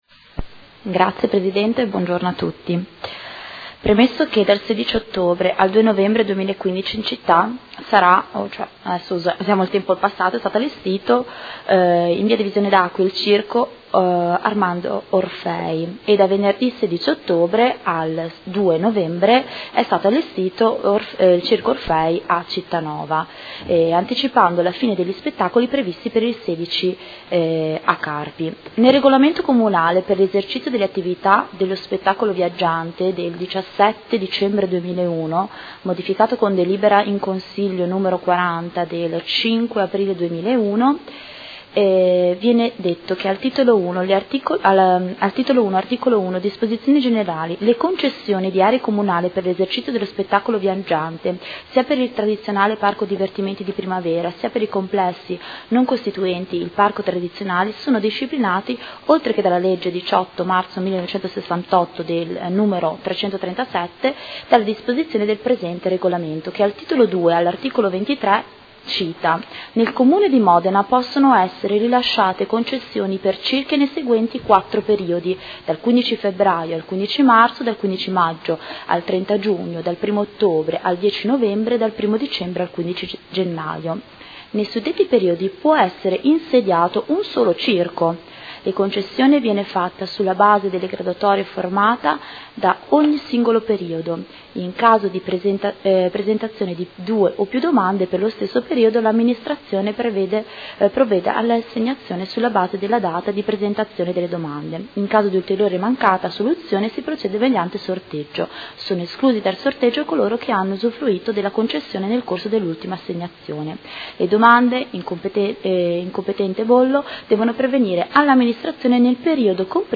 Seduta del 3/12/2015. Interrogazione del Gruppo Consiliare Movimento 5 Stelle avente per oggetto: Presenza in contemporanea di due circhi in città